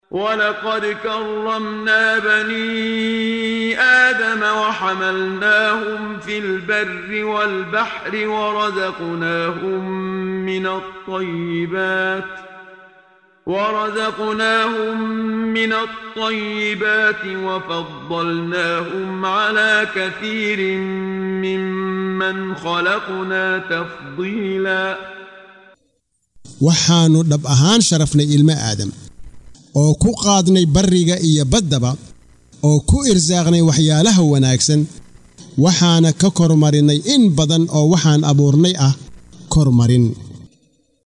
Waa Akhrin Codeed Af Soomaali ah ee Macaanida Suuradda Al-Isra ( Guuro habeen ) oo u kala Qaybsan Aayado ahaan ayna la Socoto Akhrinta Qaariga Sheekh Muxammad Siddiiq Al-Manshaawi.